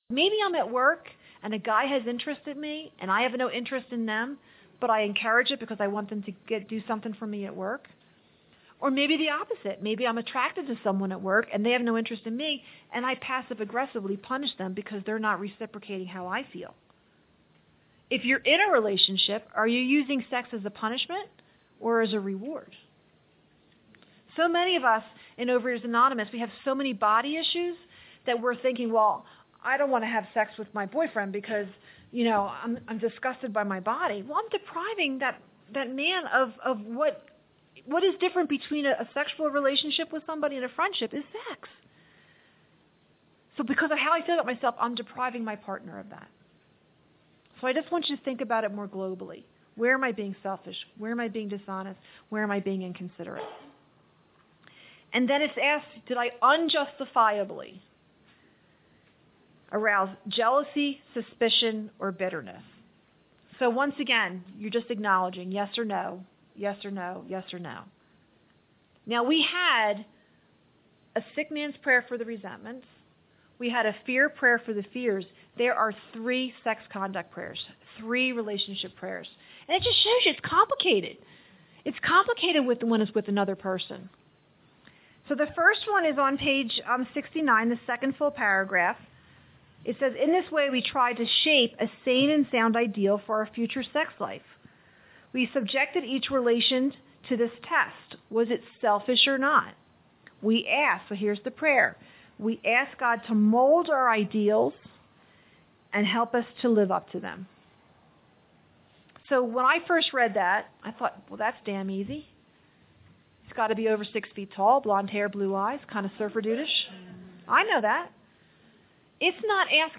Big Book Retreat 2018- Step 4- Fear/Sex “How It Works” p.68-71 (our apologies – 10 min of session was lost)
South Broadway Christian Church